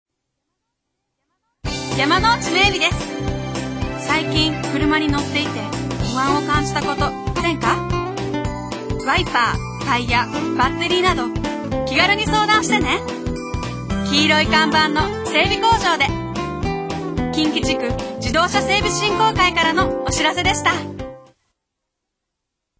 ラジオＣＭは、ＭＢＳ（毎日放送ラジオ）にて９月から11月の３ヶ月間２つの番組で20秒ＣＭを番組提供で放送します。